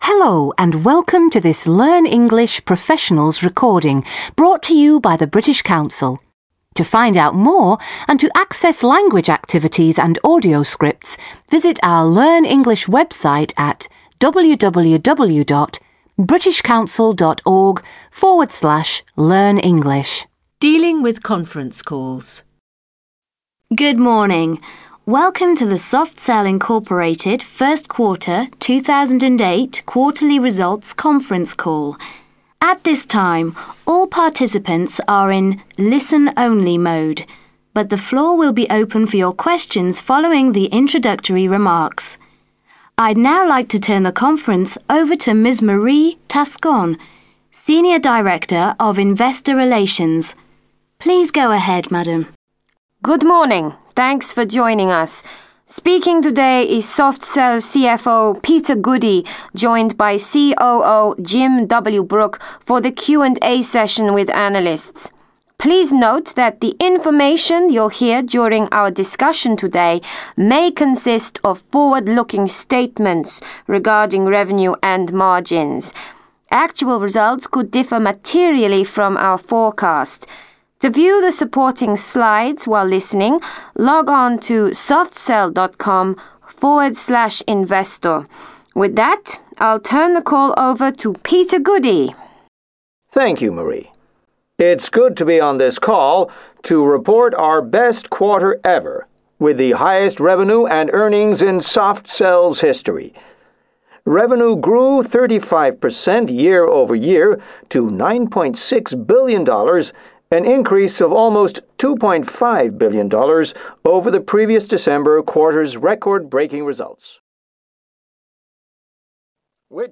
British-Council-Conference-calls.mp3